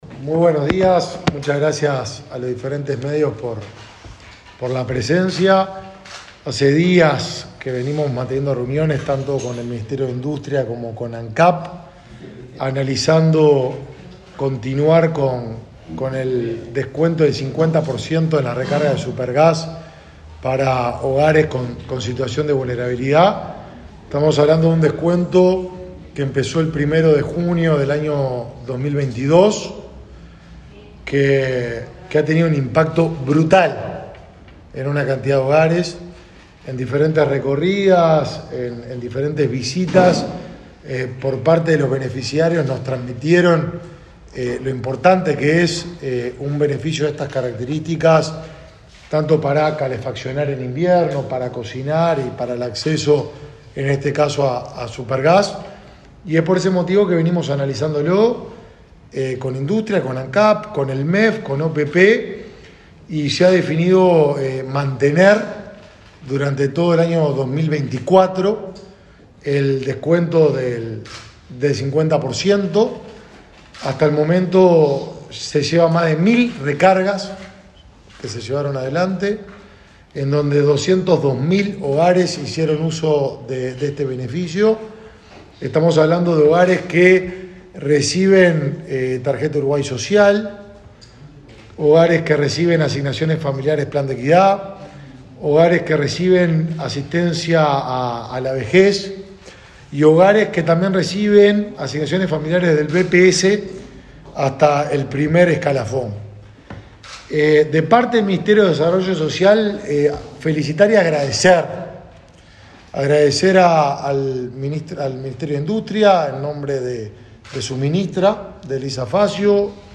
Conferencia de prensa de autoridades en el Mides
Conferencia de prensa de autoridades en el Mides 26/12/2023 Compartir Facebook X Copiar enlace WhatsApp LinkedIn El titular del Ministerio de Desarrollo Social (Mides), Martín Lema; el vicepresidente de Ancap, Diego Durand, y la ministra de Industria, Elisa Facio, informaron a la prensa sobre la extensión de la bonificación del 50% en las recargas de garrafas de supergás para beneficiarios de programas del Mides.